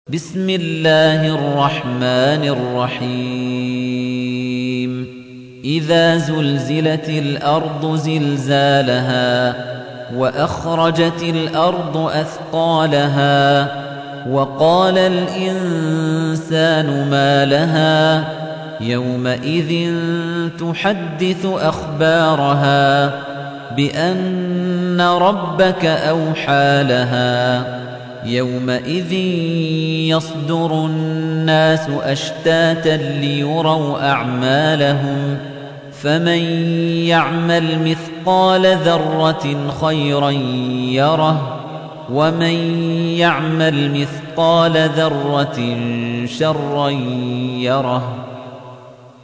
تحميل : 99. سورة الزلزلة / القارئ خليفة الطنيجي / القرآن الكريم / موقع يا حسين